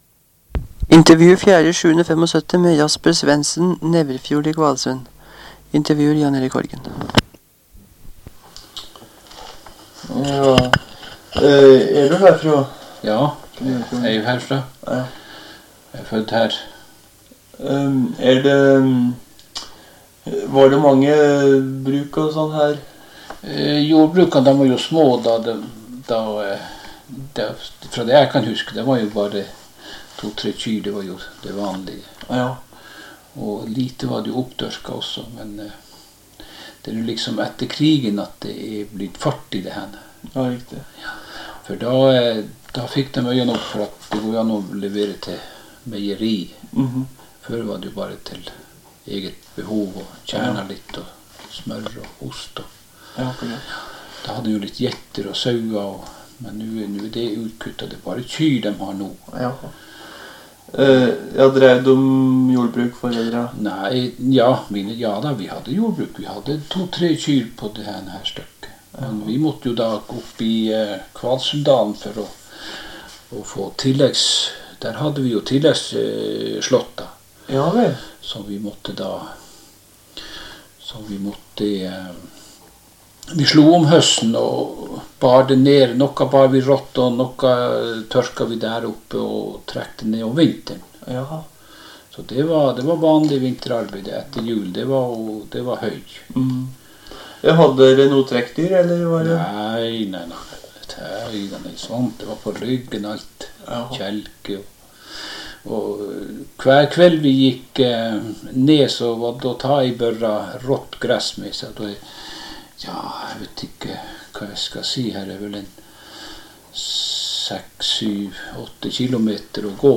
Sted: Kvalsund, Neverfjord